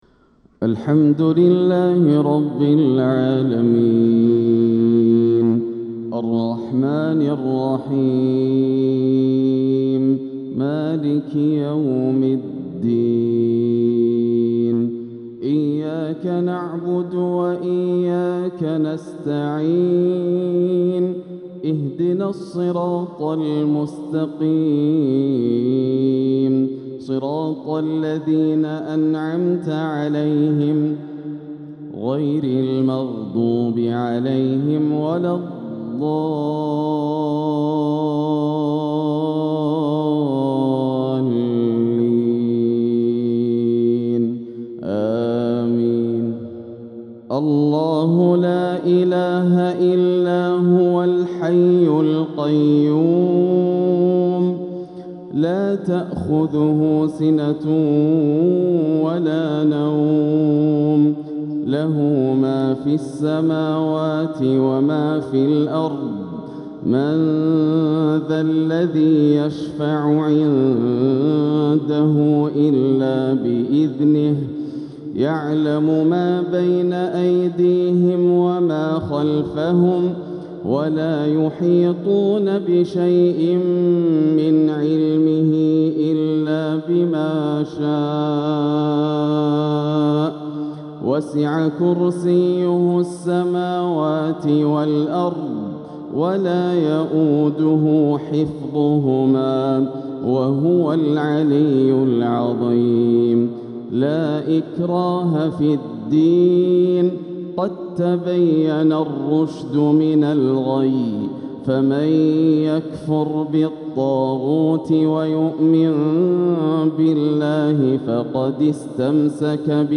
تلاوة من سورة البقرة 255-260 | فجر الأربعاء 4-7-1447هـ > عام 1447 > الفروض - تلاوات ياسر الدوسري